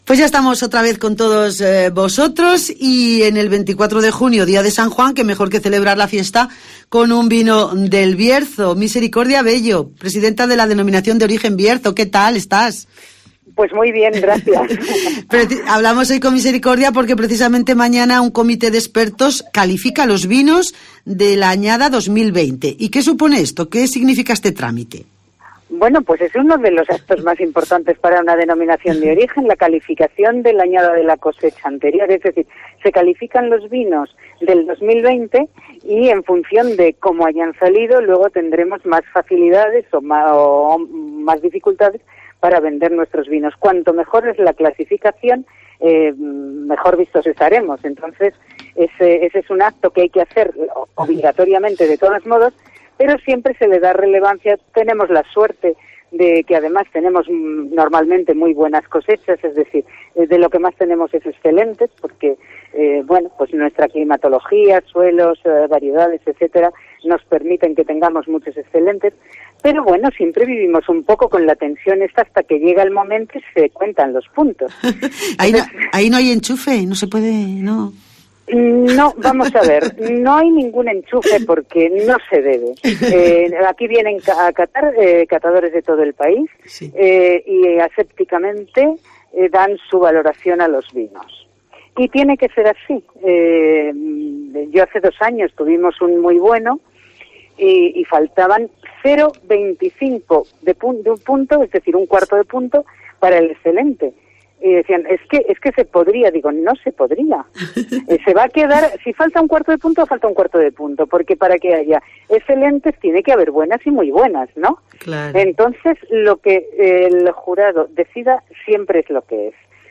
entrevista
en el Mediodía COPE en la provincia de León